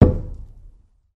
国内声音 " 大块的木头被扔下 04
描述：在一个水泥地板上下落的大槭树日志 用数字录音机录制并使用Audacity处理